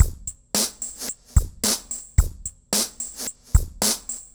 RemixedDrums_110BPM_21.wav